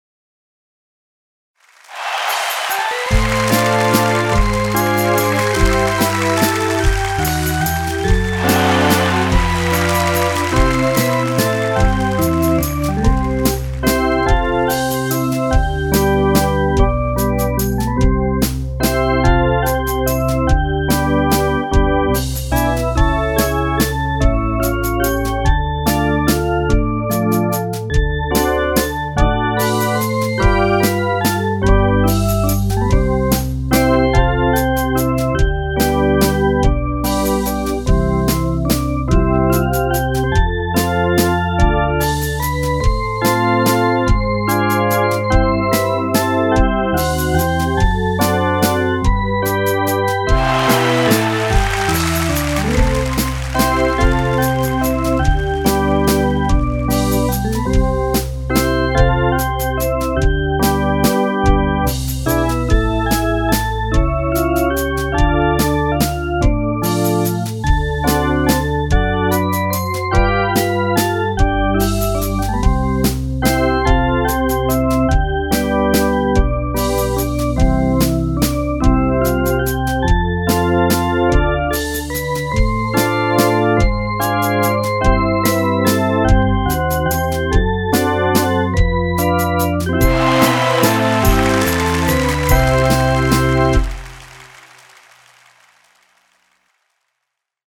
Accidentals, C Major Scale Song.